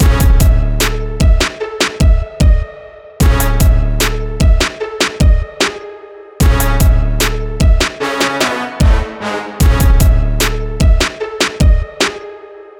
• trap upfront sample.ogg
trap_upfront_sample_epd.wav